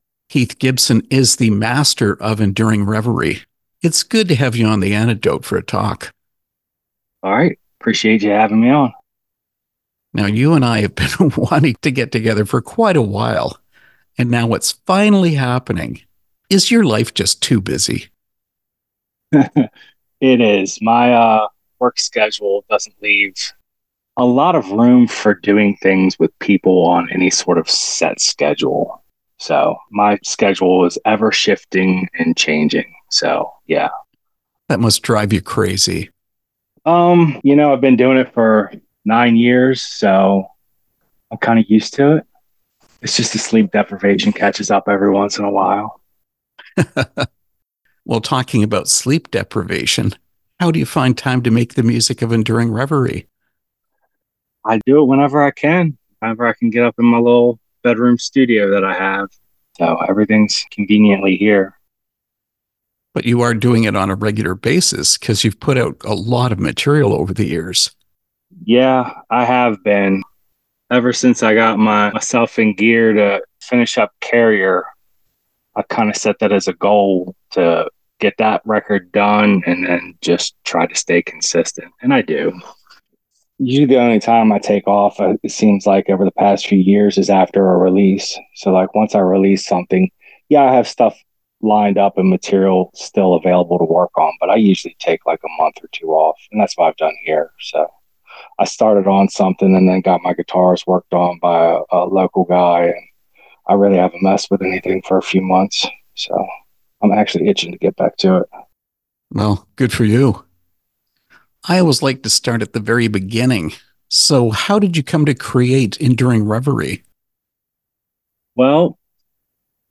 Interview with Enduring Revery
enduring-revery-interview.mp3